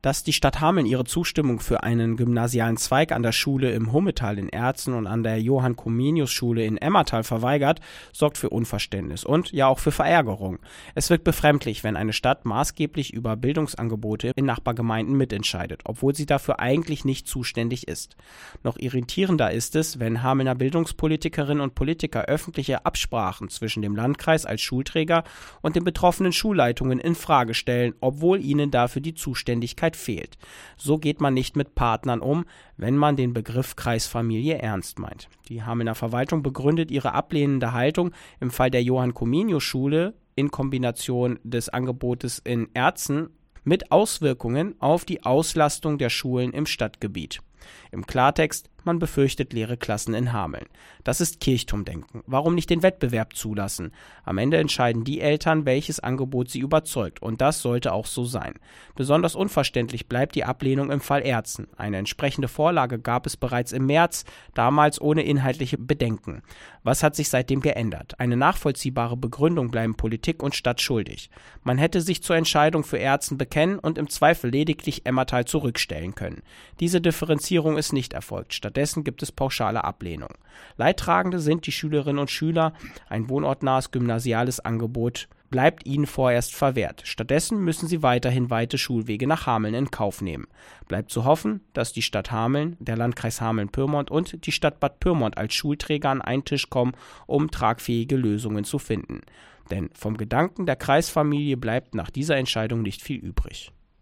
Warum Hameln gegen ein gymnasiales Angebot in Aerzen und Emmerthal ist (mit Kommentar)